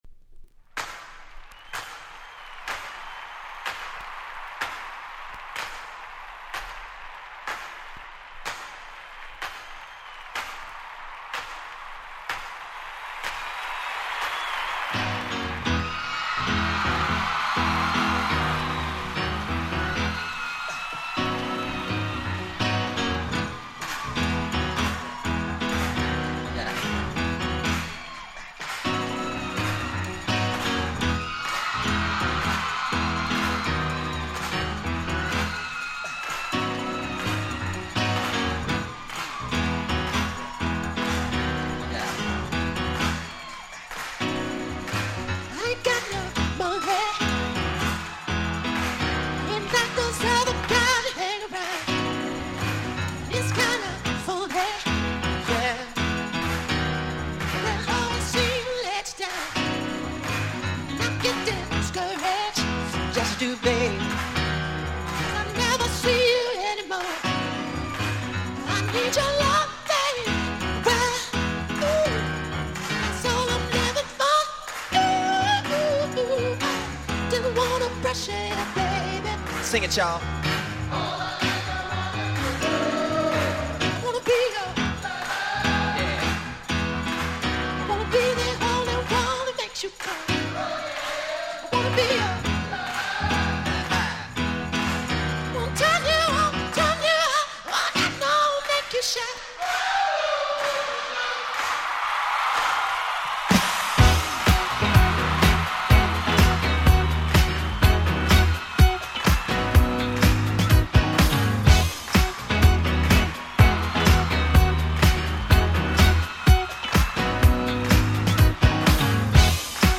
Nice Disco Boogie Remix !!
とにかく展開が最高！！
よりフロア仕様に、よりドラマティックに変身した鬼使える1枚です！